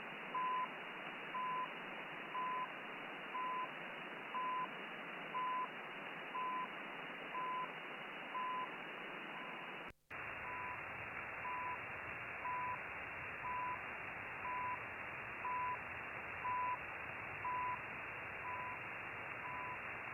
Both devices were operated with the same settings and were supplied with the antenna signal via the antenna distributor of Elad ASA-62.
Second 10 - 20> Winradio G33DDC Excalibur Pro
USB-2.8KHz